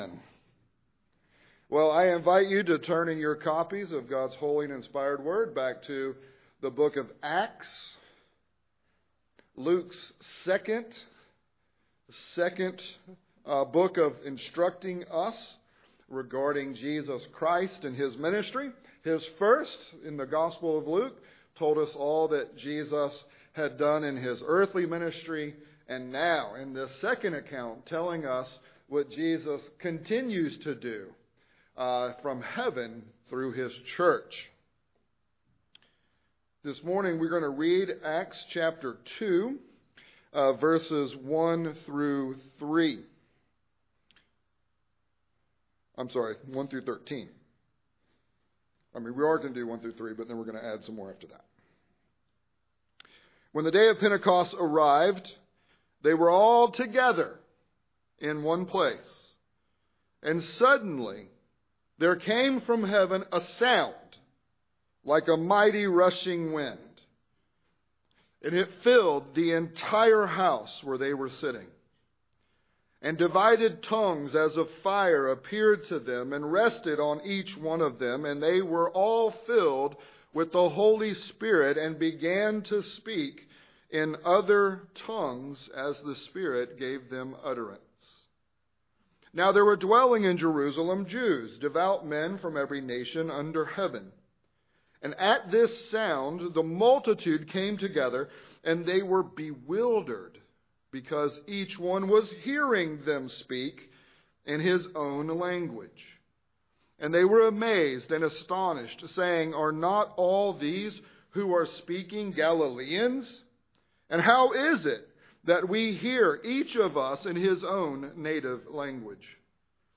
Pentecost Revising Babel ~ Sermons Podcast
Sermons from Grace Covenant Church: Dallas, GA